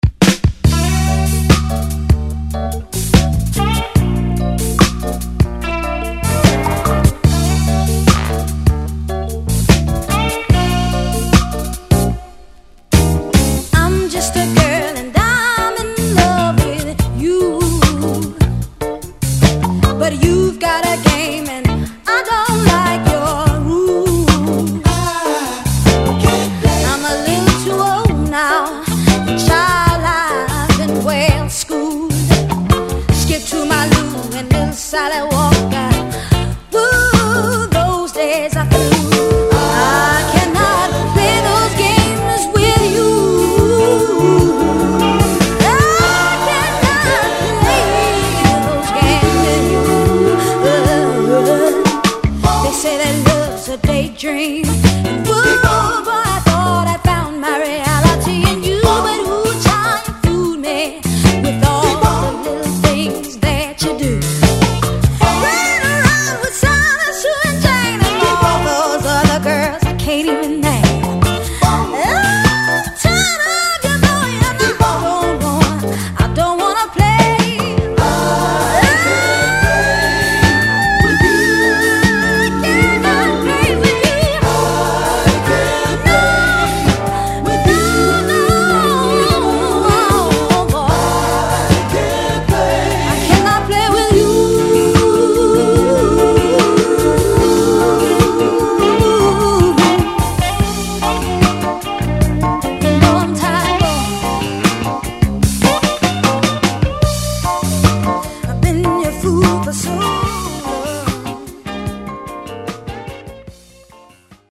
Urban Mid Soul！